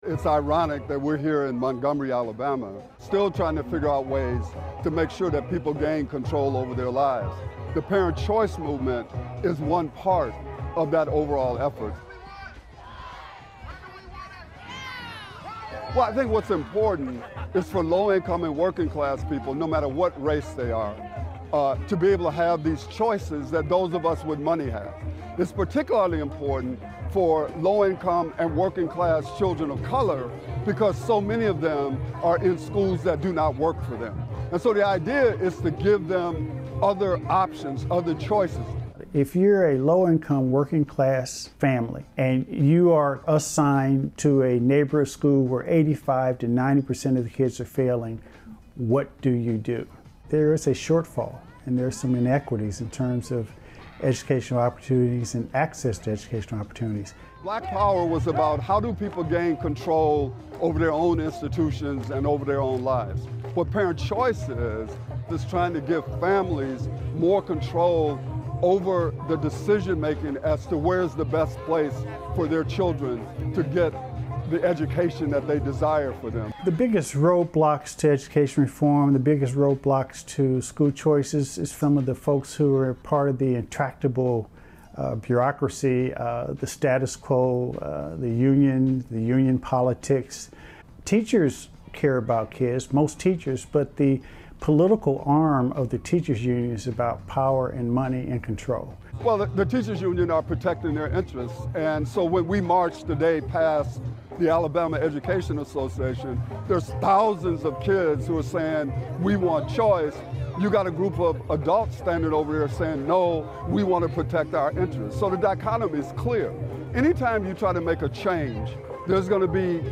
On Wednesday, January 28th, thousands of parents and students marched down the streets in Montgomery, Alabama, demanding that their state recognize the growing need for more options in elementary and secondary education.